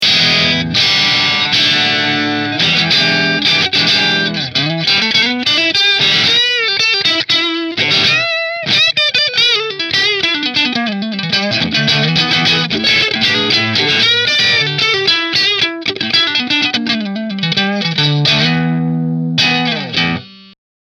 Fender Partscaster Position 1 Through Marshall